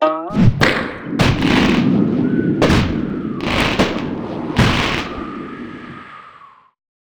slingshot.wav